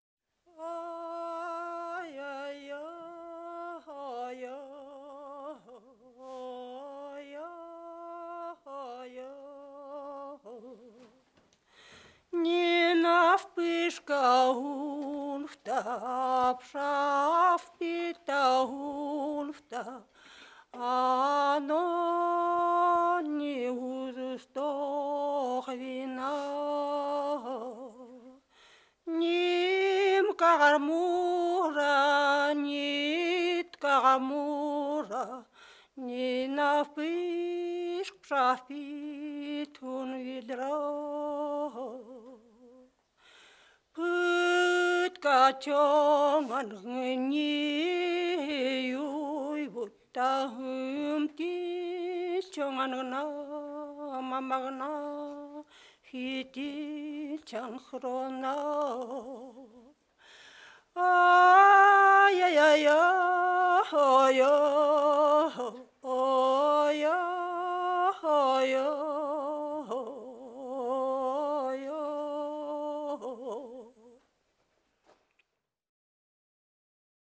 Песня коренных народов Сахалина
pesni_narodov_sahalina.mp3